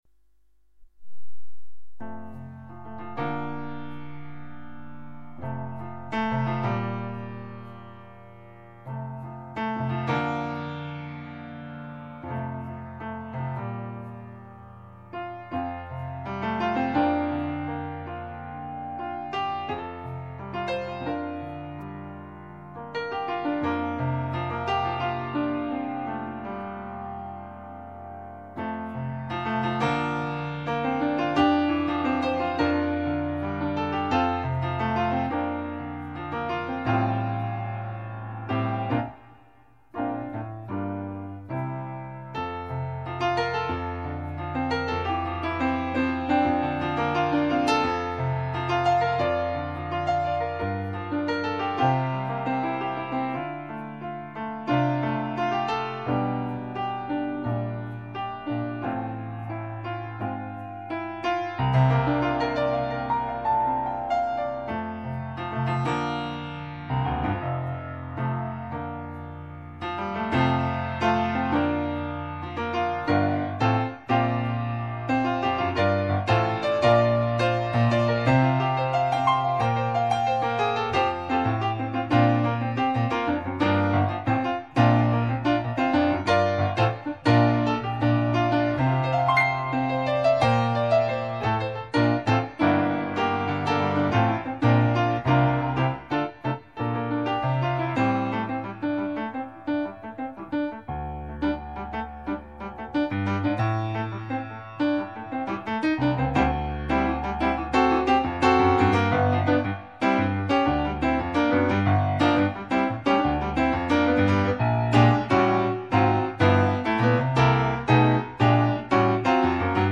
Sunrise (Improvisation)